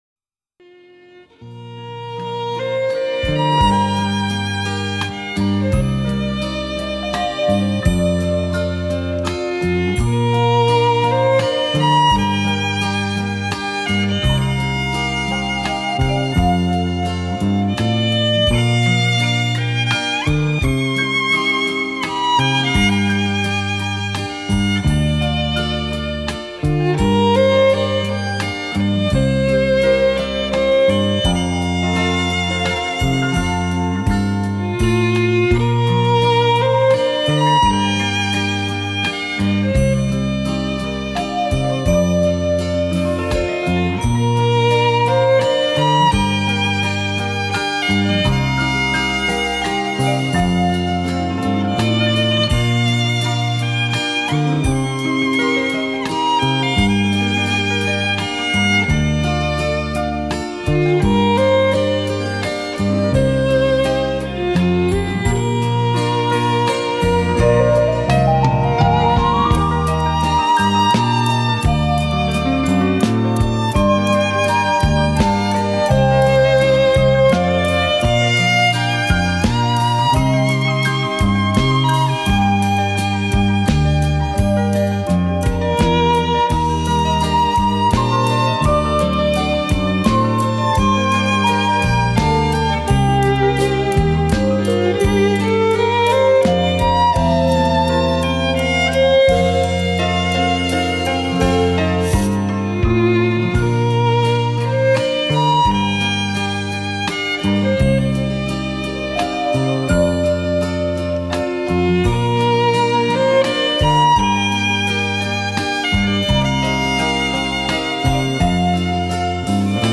两者梦幻般的演绎，编织出一幅幅浪漫如诗的画面，让你深深地陶醉于浓情蜜意的旋律之中......